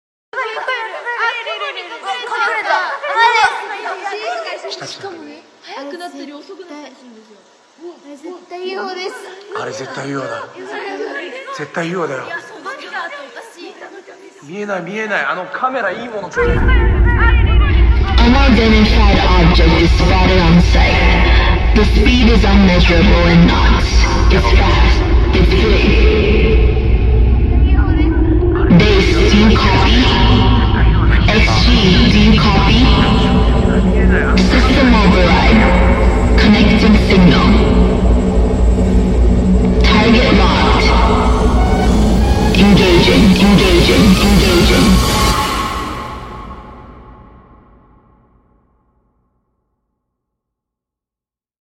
KPop